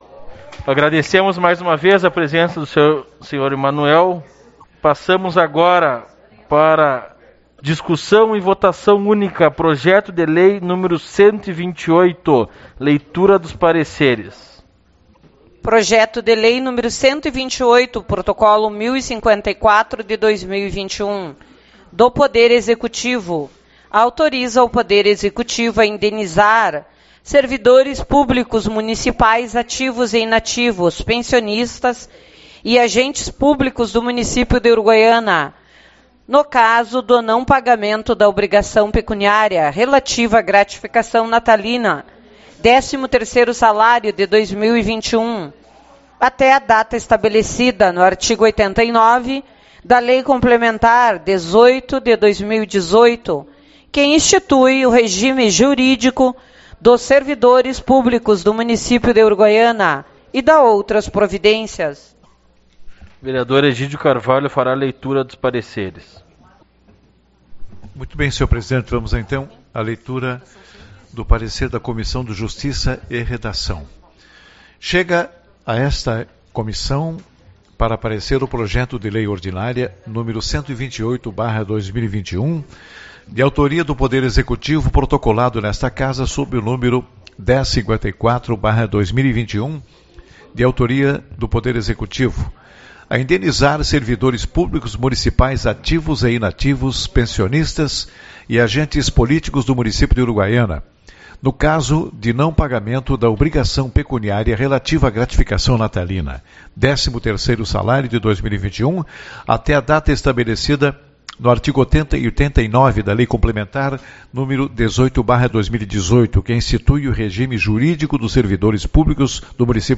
19/10 - Reunião Ordinária